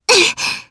Frey-Vox_Casting1_jp.wav